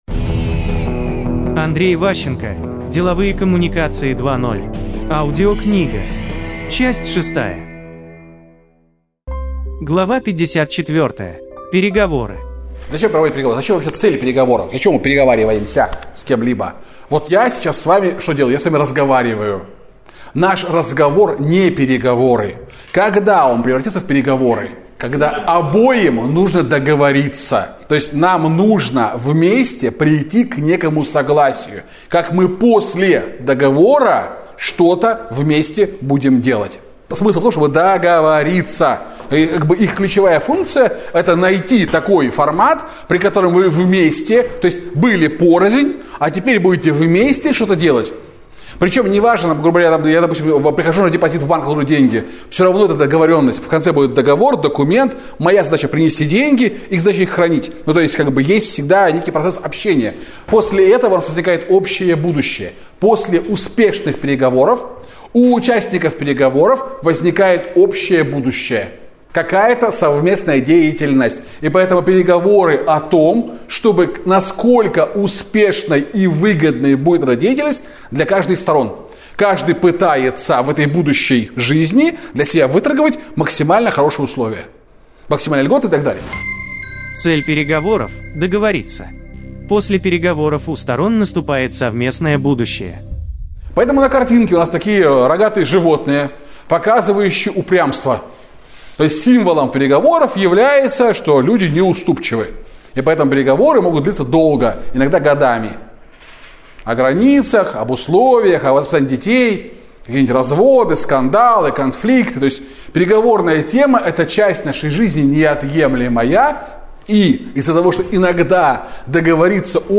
Аудиокнига Деловые коммуникации 2.0. Часть 6 | Библиотека аудиокниг